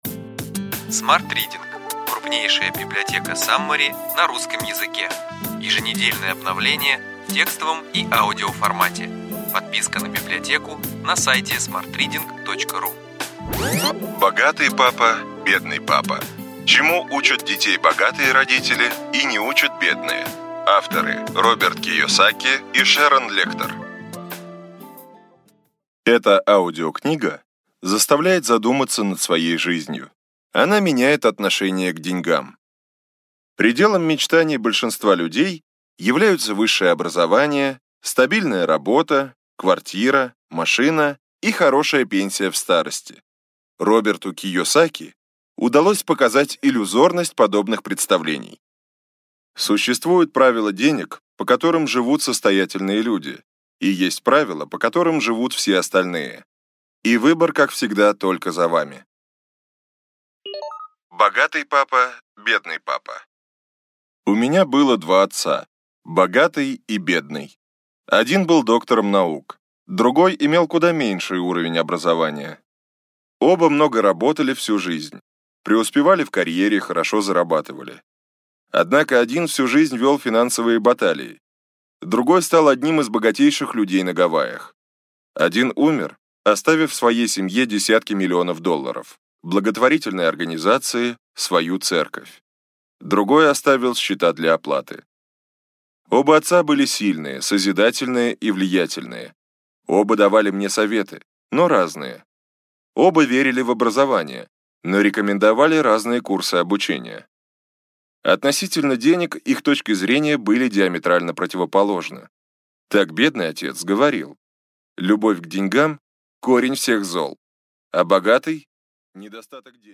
Аудиокнига Ключевые идеи книги: Богатый папа, бедный папа. Чему учат детей богатые родители и не учат бедные.